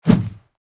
Whoosh.wav